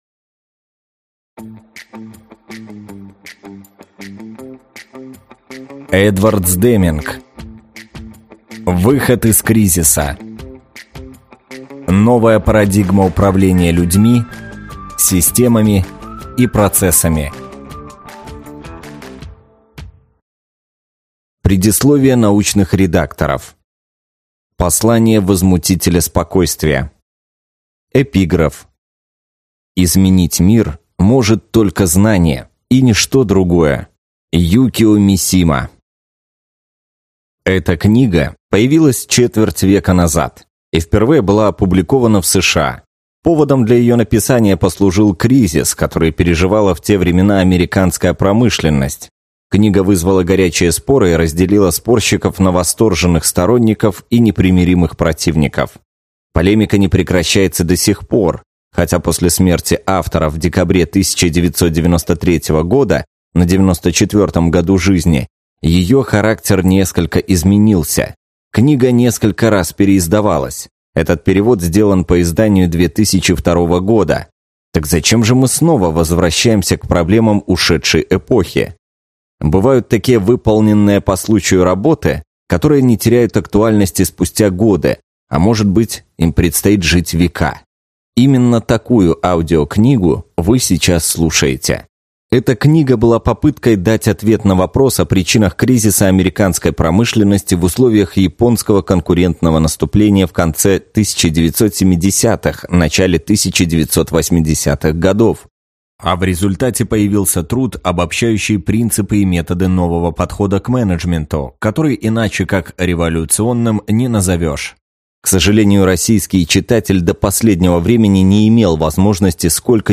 Аудиокнига Выход из кризиса. Новая парадигма управления людьми, системами и процессами | Библиотека аудиокниг